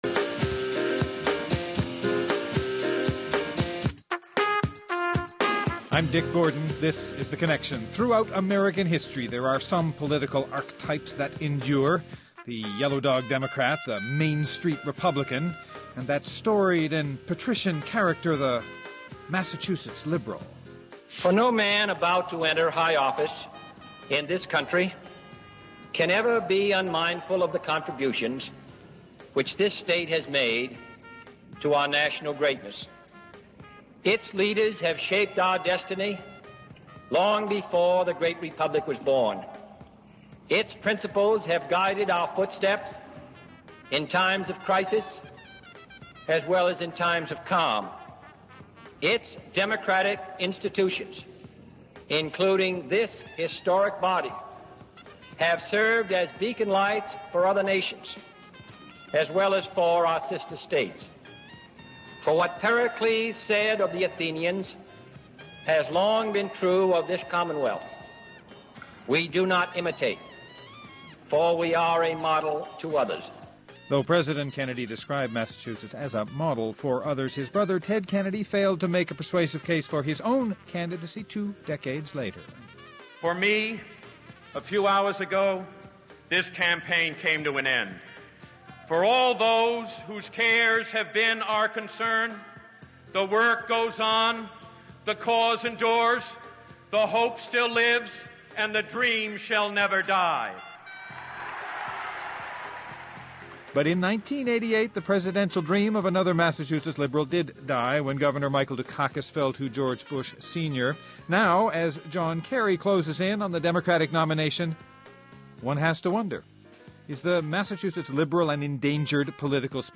political analyst and syndicated columnist